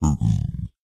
Minecraft Version Minecraft Version latest Latest Release | Latest Snapshot latest / assets / minecraft / sounds / mob / zombified_piglin / zpig4.ogg Compare With Compare With Latest Release | Latest Snapshot